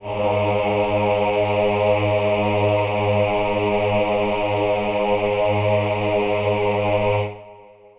Choir Piano (Wav)
G#2.wav